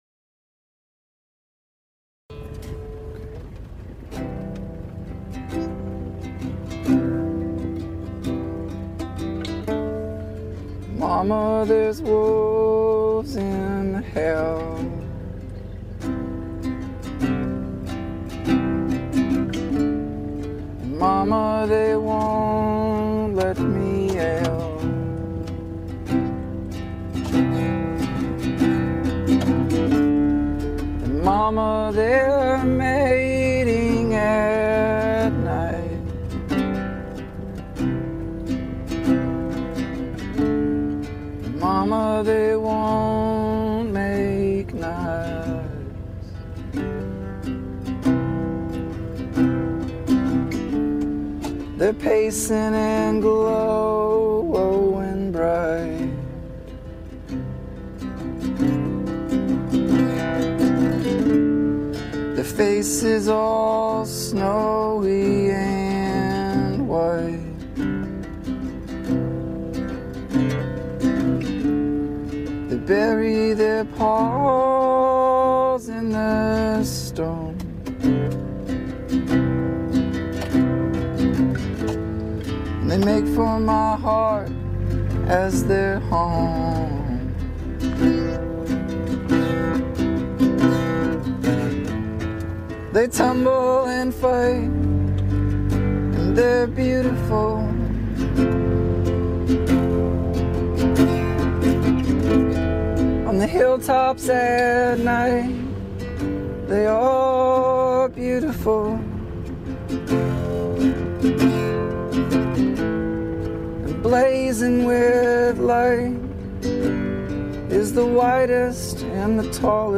The song echoes, pines and just hits the spot.
ukulele